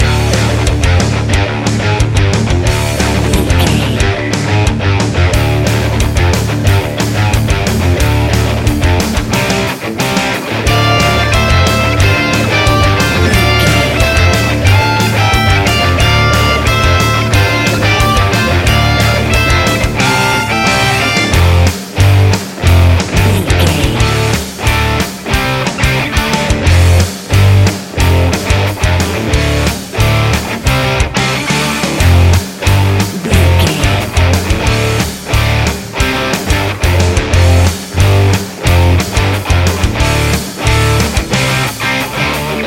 Aeolian/Minor
Fast
aggressive
industrial
intense
driving
dark
heavy
bass guitar
electric guitar
drum machine